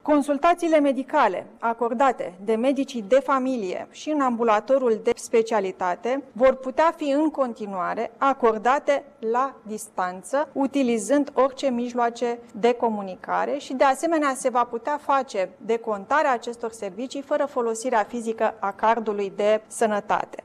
În continuare medicii de familie pot da consultaţii la distanţă, a precizat vicepremierul Raluca Turcan :